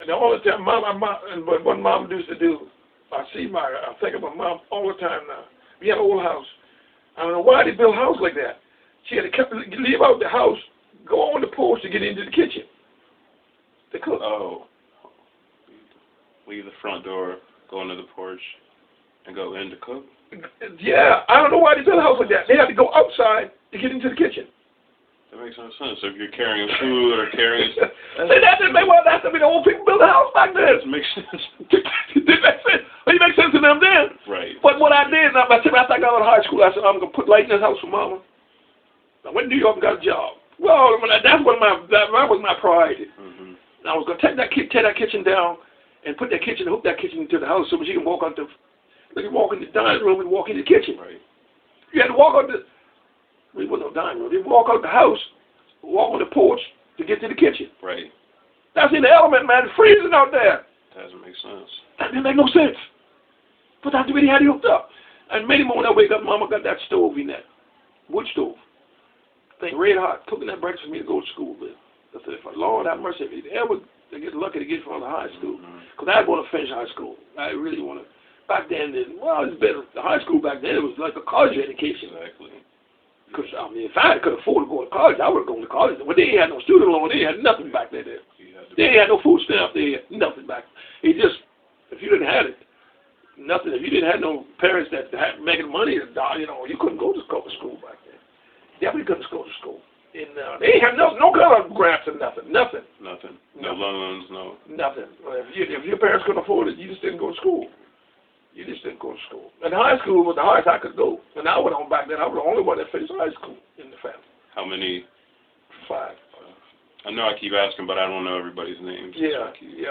Unpublished oral interview, Murraysville Community, Moncks Corner, SC